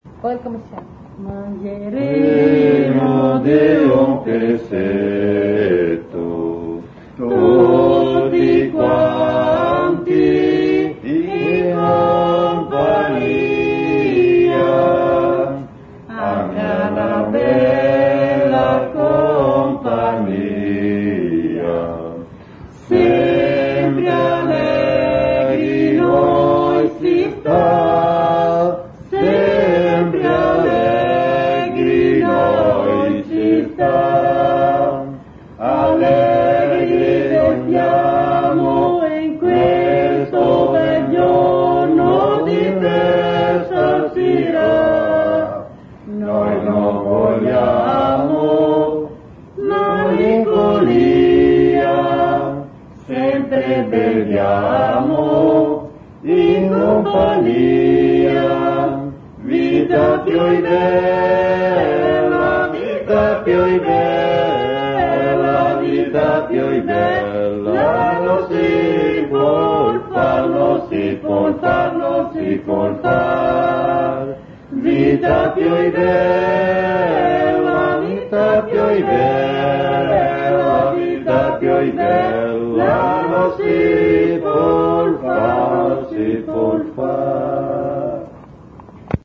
Preghiera